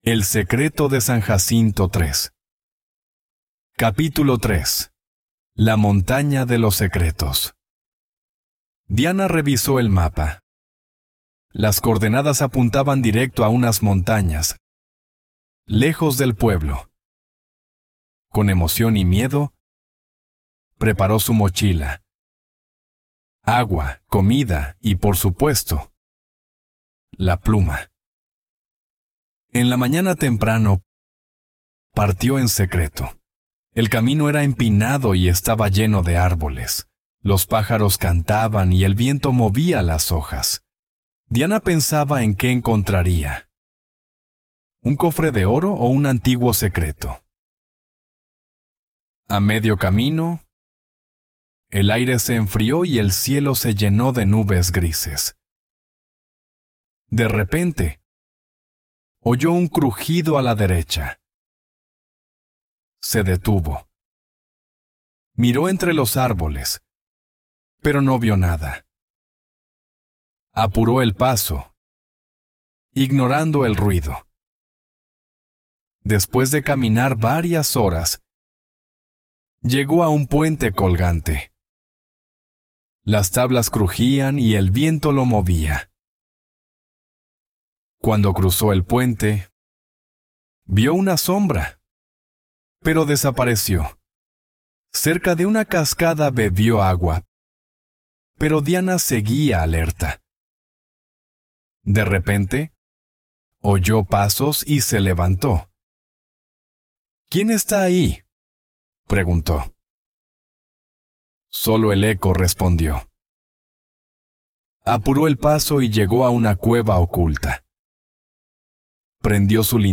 Spanish online reading and listening practice – level B1